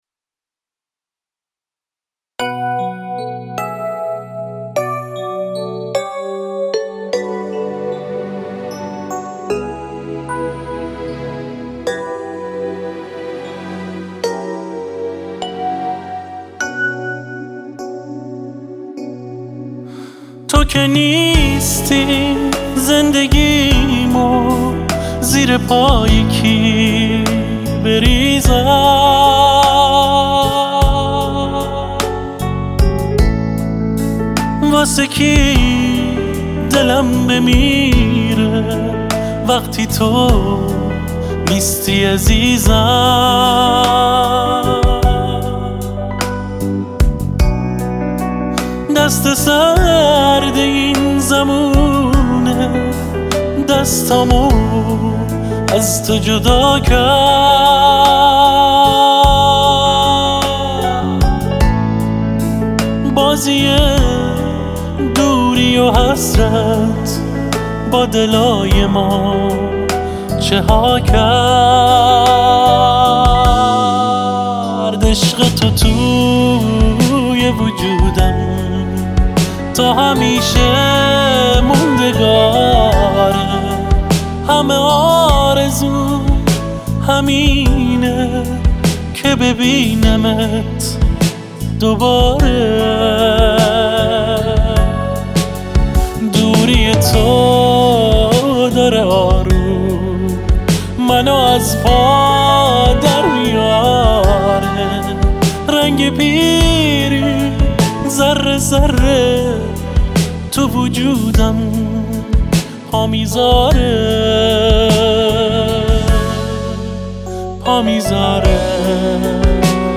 احساسی و غمگین